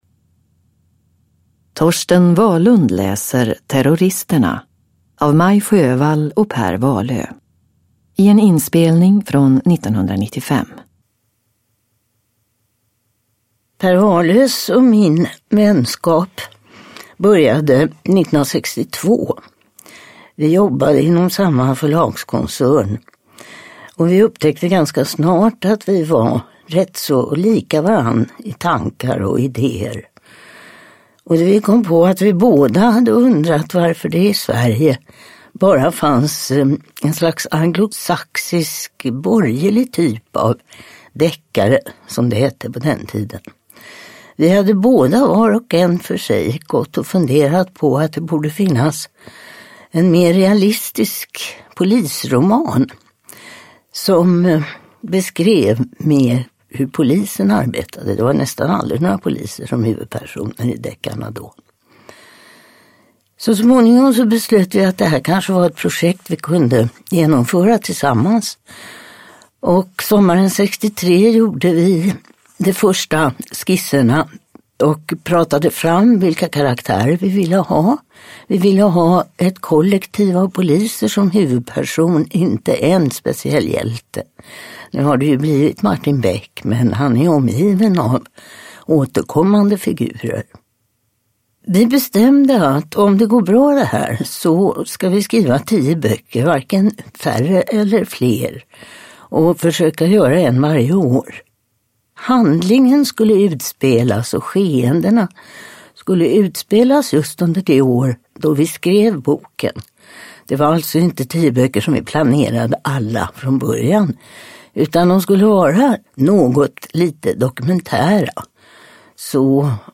Terroristerna (ljudbok) av Sjöwall Wahlöö